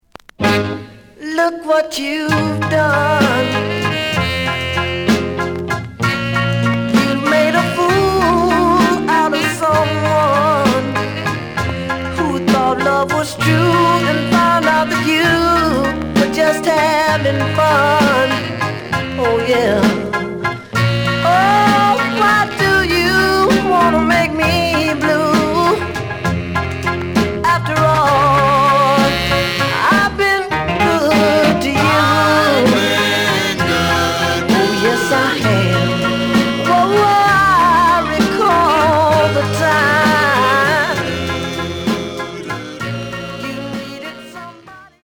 The audio sample is recorded from the actual item.
●Genre: Soul, 60's Soul
Some click noise on both sides due to scratches.)